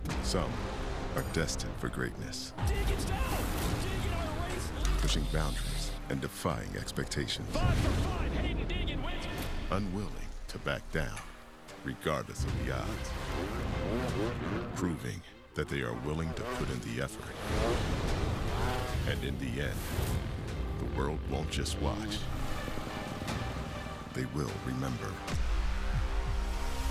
Male voice actor
Narrator, Genuine, Articulate, Tough, Authoritative, Gritty, Deep